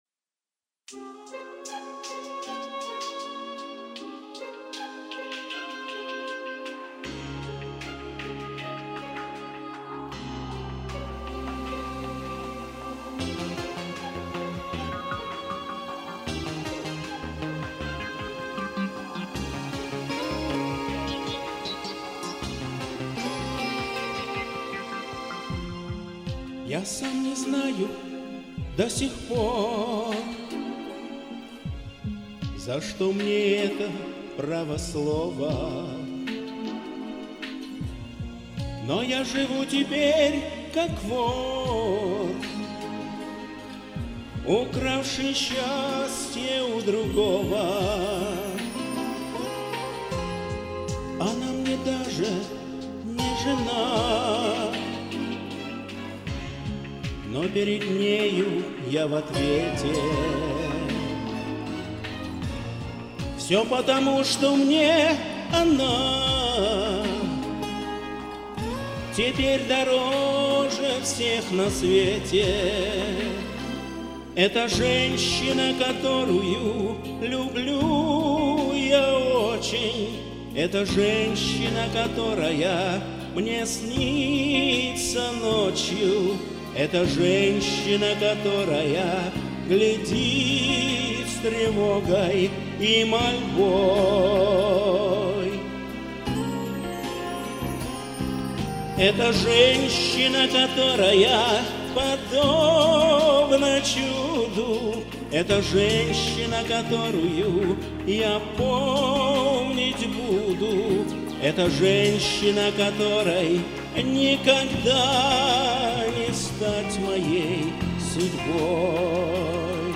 спокойная, мелодичная
Спел очень трогательно и ....Мне очень понравилось!!!